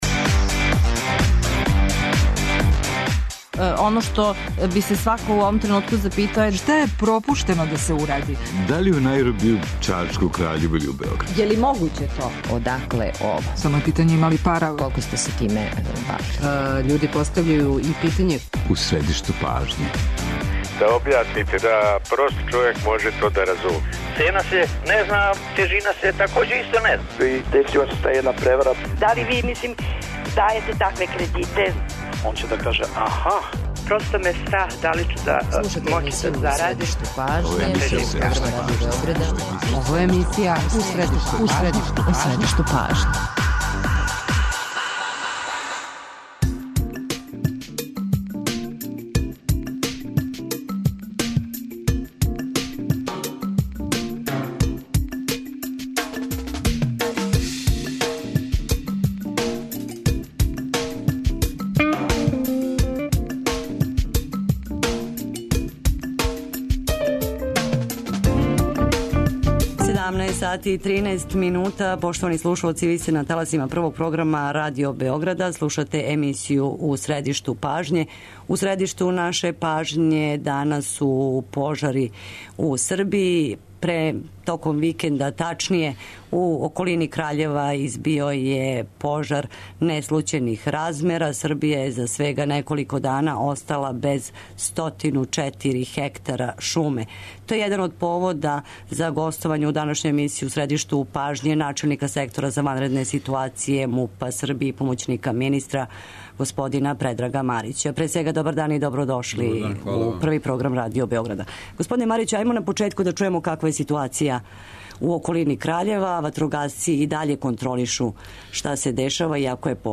То су нека од питања за нашег госта, начелника Сектора за ванредне ситуације Предрага Марића.